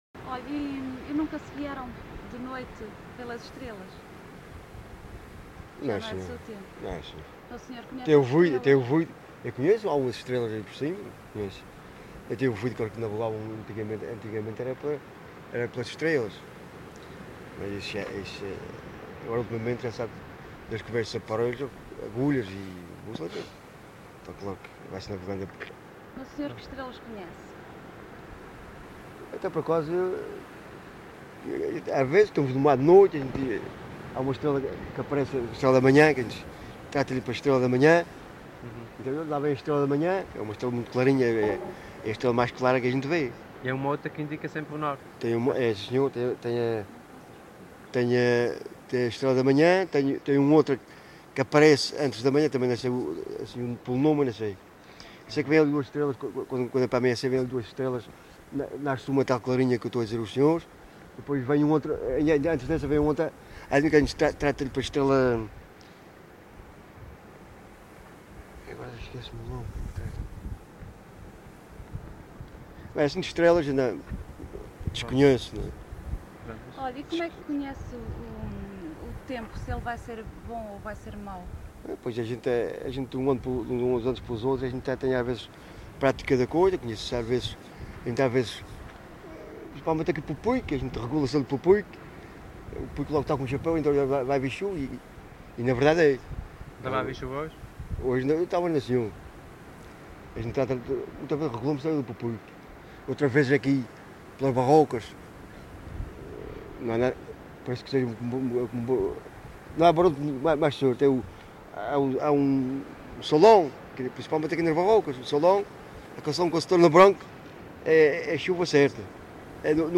LocalidadeSanta Cruz da Graciosa (Santa Cruz da Graciosa, Angra do Heroísmo)